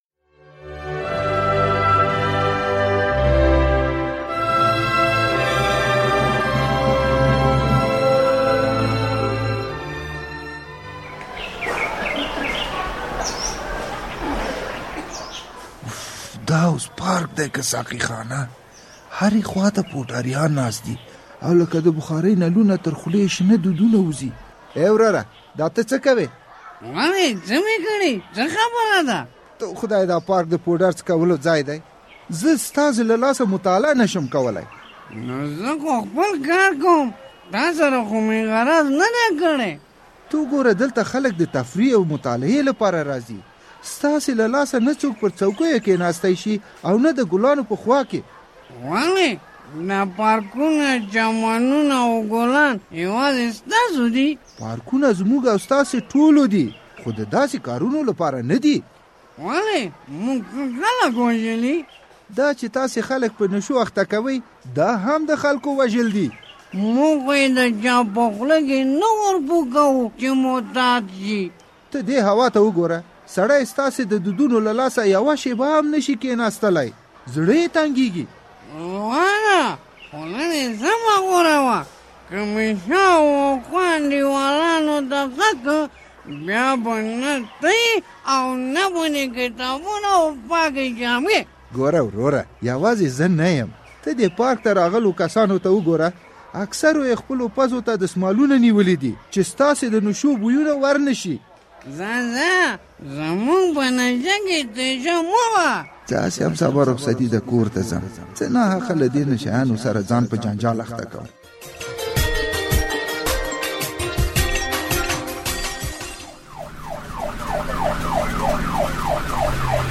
د زهرو کاروان ډرامه